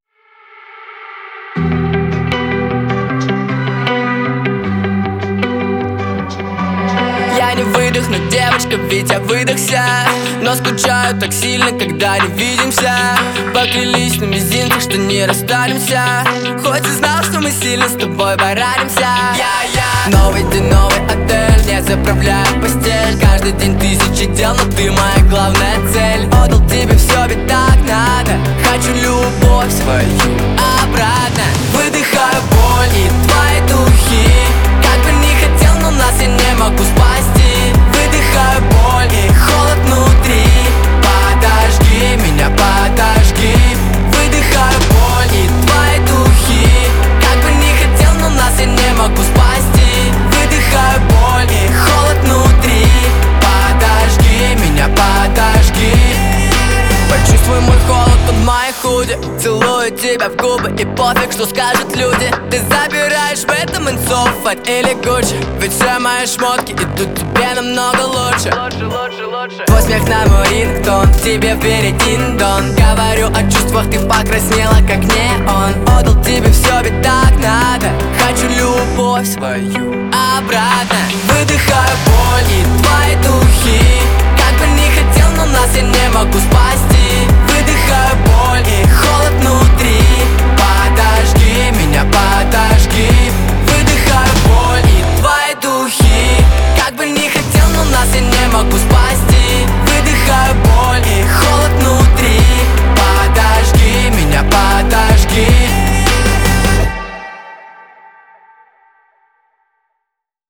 выполненный в жанре поп с элементами электронной музыки.